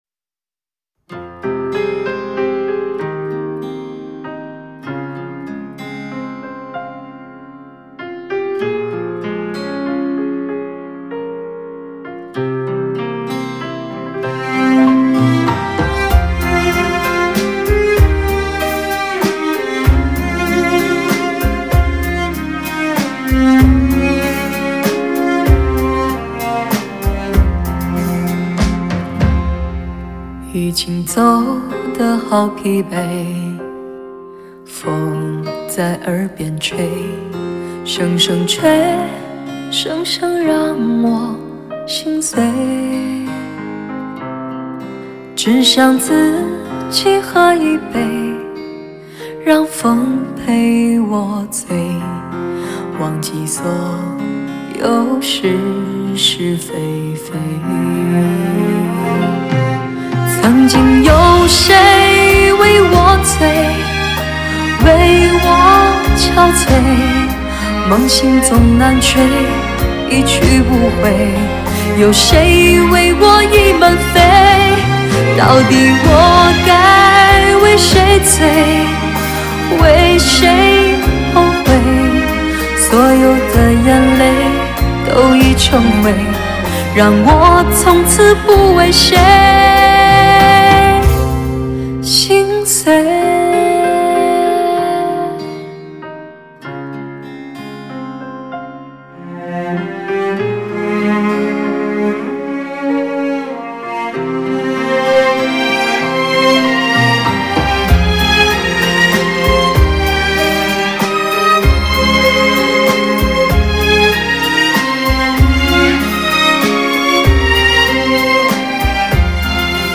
一张集极品人声的经典发烧大碟。